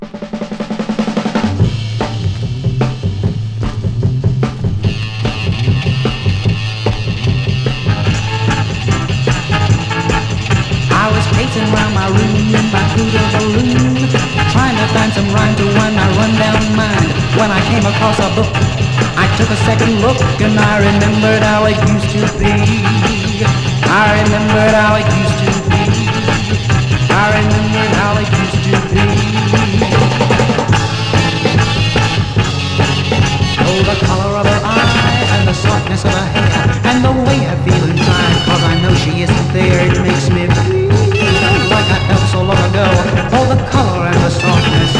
盤面から溢れる猛る音、それぞれに気合いが漲る様が痛快とも思える心地良さ。
Rock, Garage, Psychedelic　France　12inchレコード　33rpm　Mono